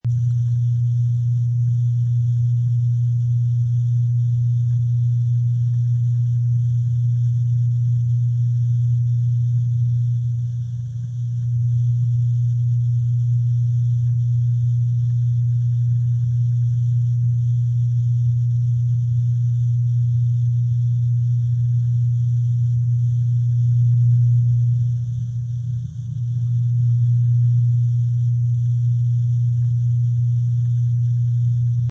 Download Mechanical sound effect for free.
Mechanical